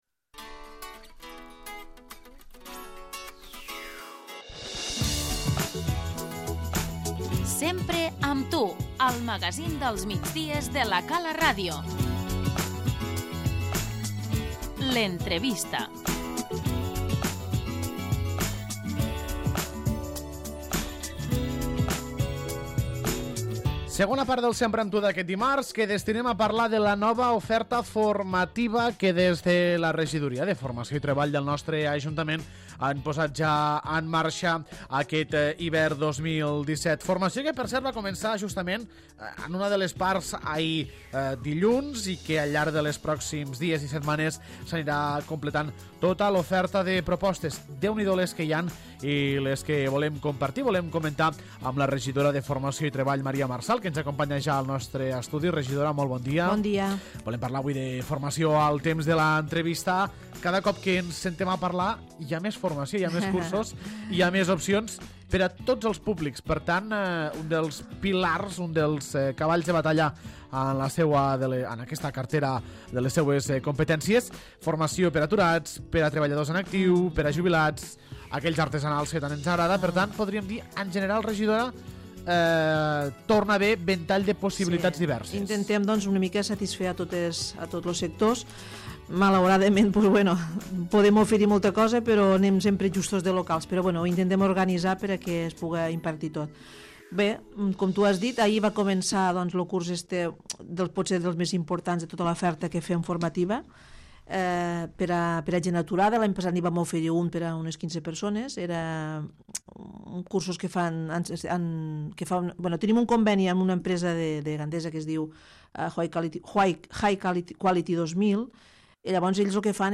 L'entrevista - Maria Marsal, regidora de Formació i Treball
La regidoria de Formació i Treball ha presentat l'oferta formativa per aquest hivern. Repassem totes les propostes de cursos i tallers amb la regidoria Maria Marsal.